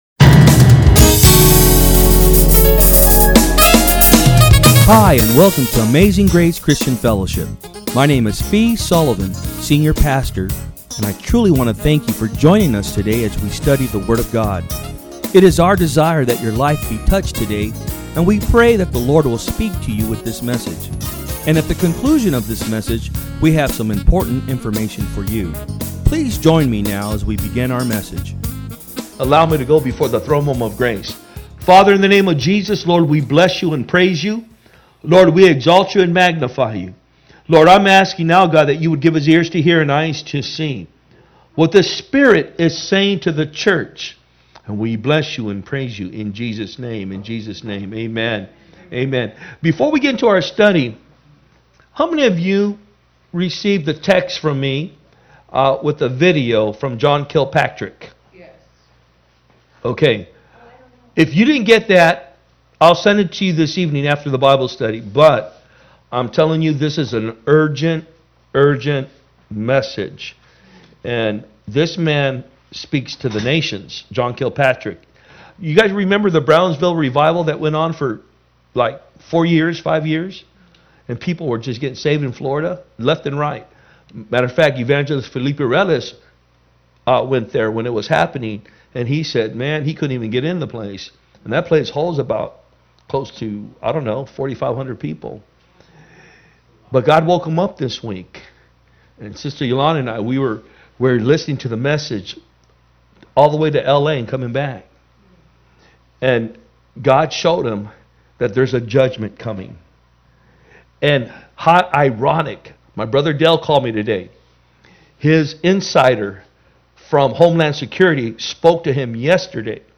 Message
From Service: "Wednesday Pm"